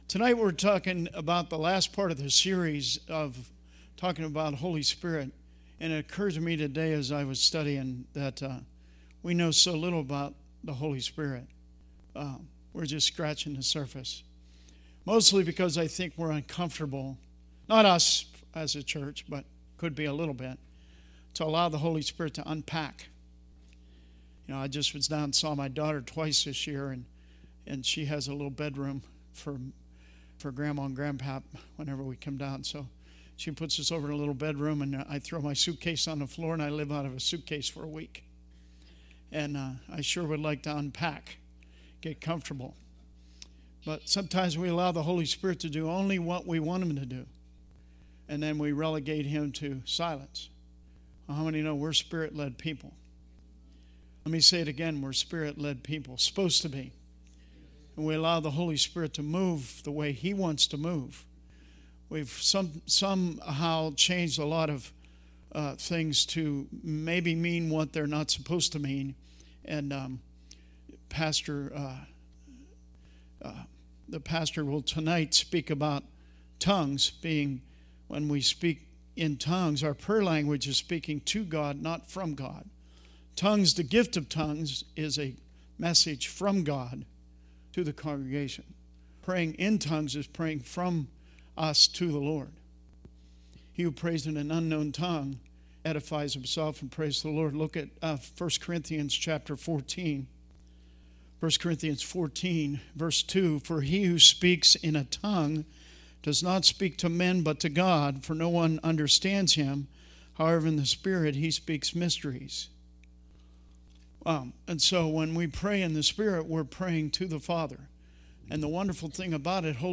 Service Type: Wednesday Teaching